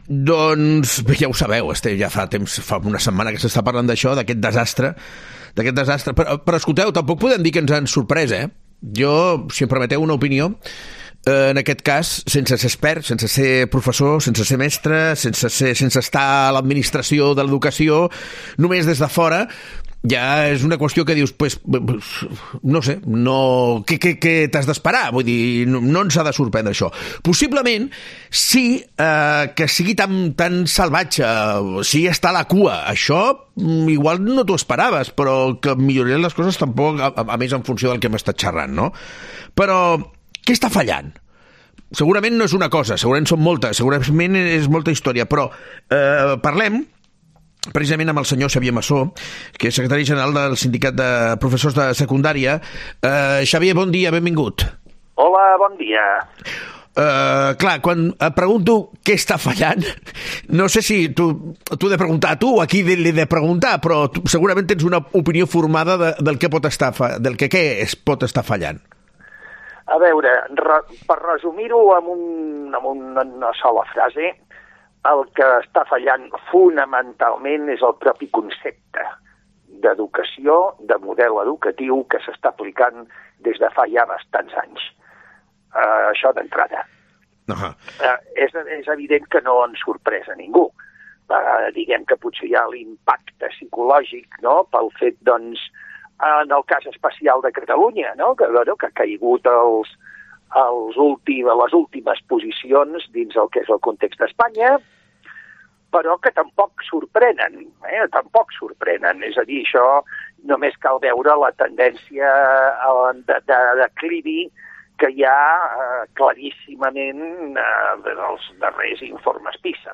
Entrevista en "Herrera en Cope"